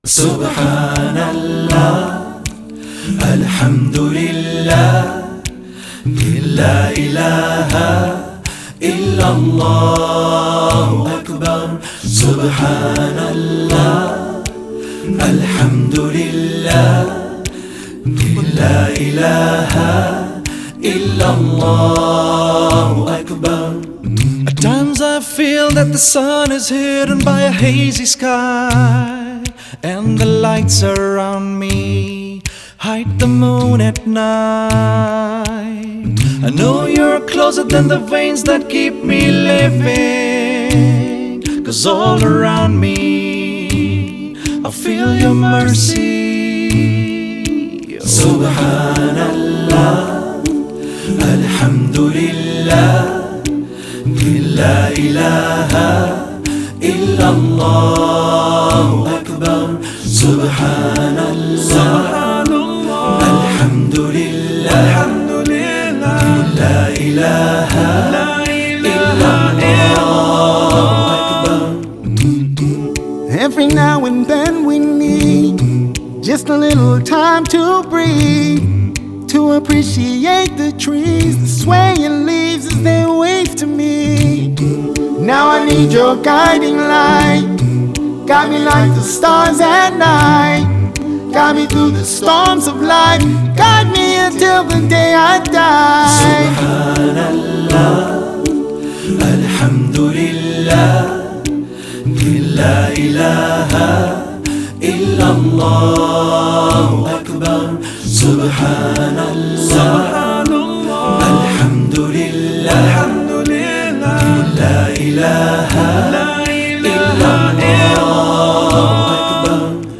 Hamd and Naats